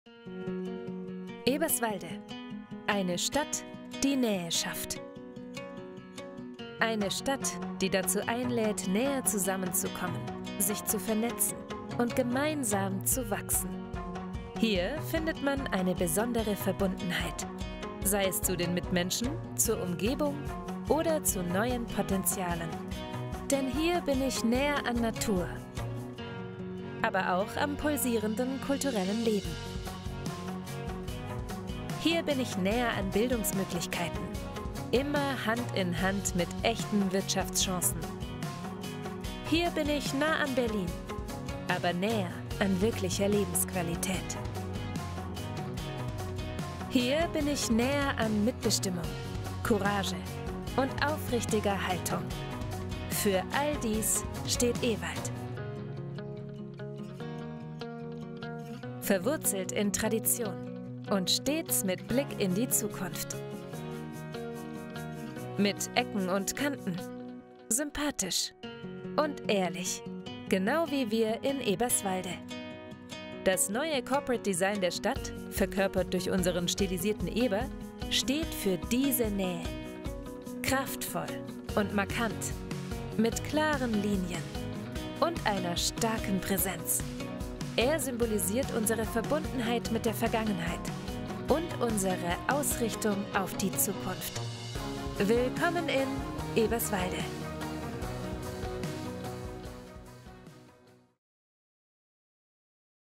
Sprechprobe: Industrie (Muttersprache):
Imagefilm_Eberswalde.mp3